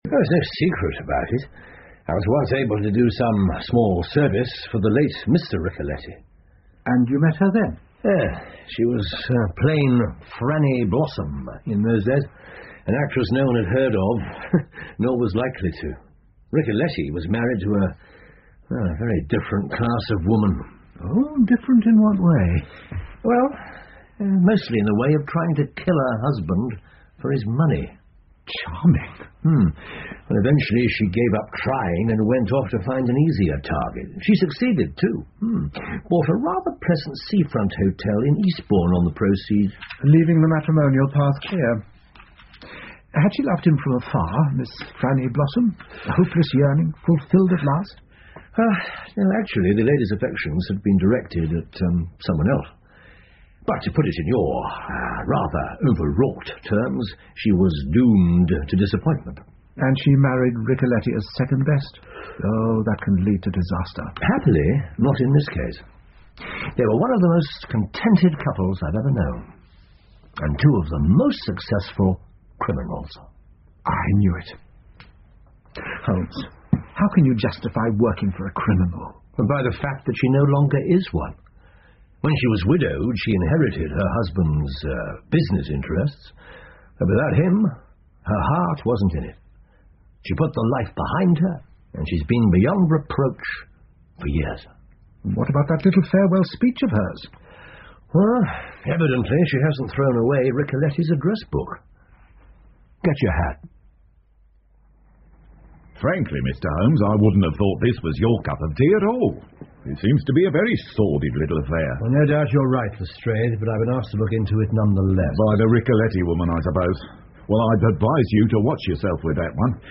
福尔摩斯广播剧 The Striking Success Of Miss Franny Blossom 4 听力文件下载—在线英语听力室